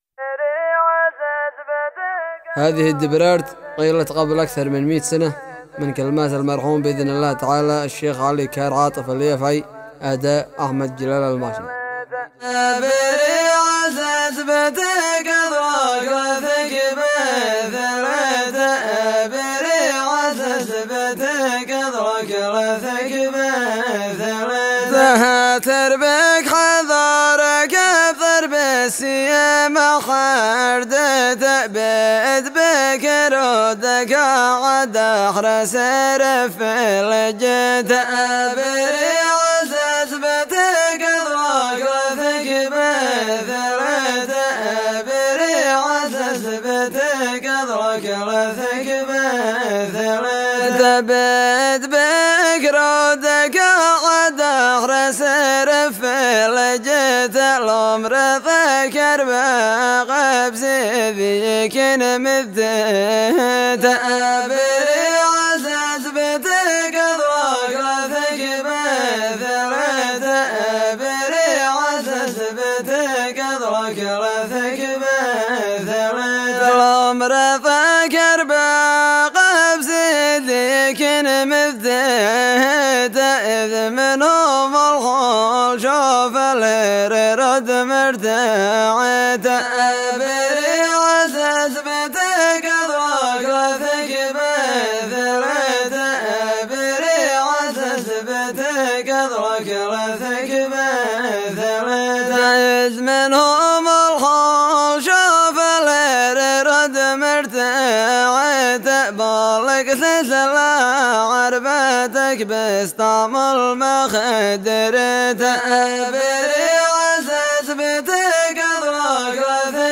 دبرارت ، ، نانا محليه ، صلاله ، ظفار ، فن الريفي ، تراث ، تراث ظفار ، فن النانا